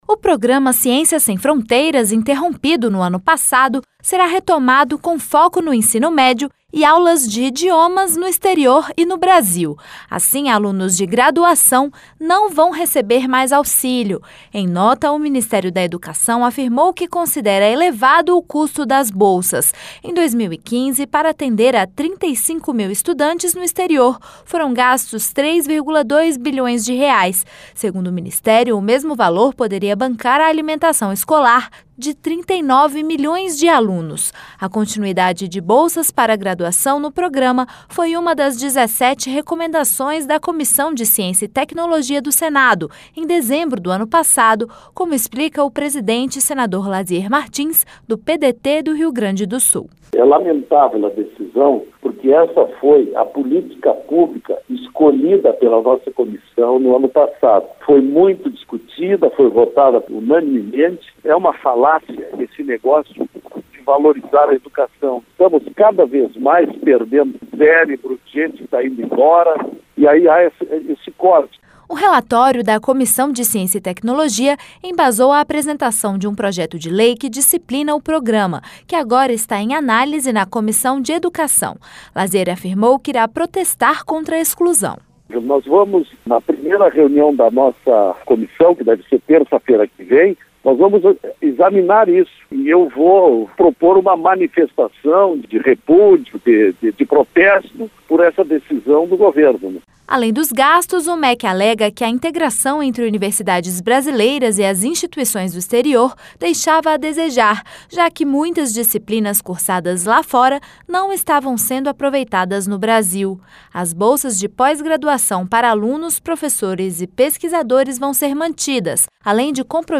Senador Lasier Martins